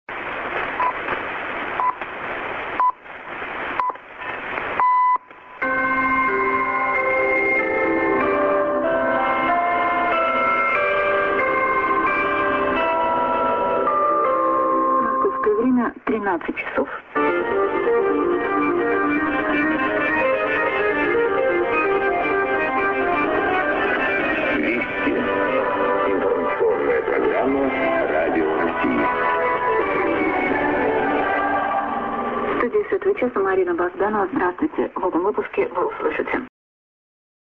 ->TS->TS->ID(women+man)　USB R.Sakharinsk(Radio Rossii)